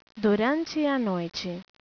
In this page, you can hear some brazilian portuguese words/phrases.